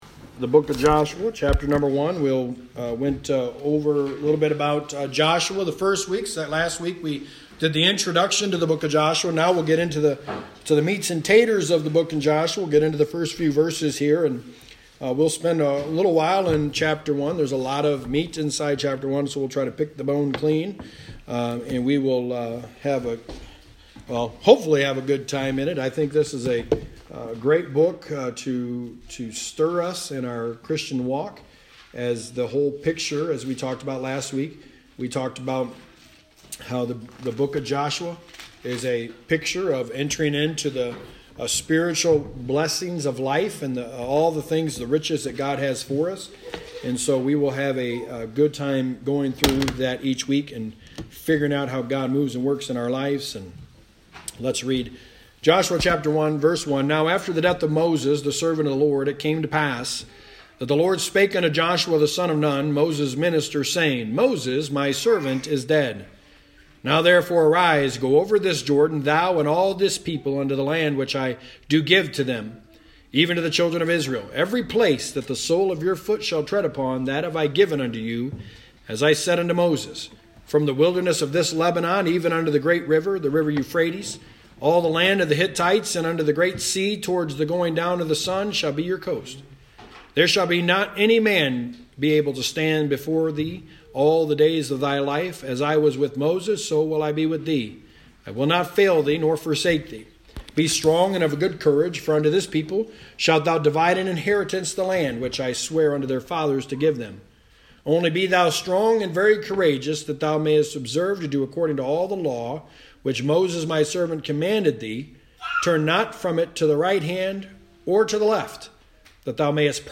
The Book of Joshua: Sermon 3
Service Type: Sunday Morning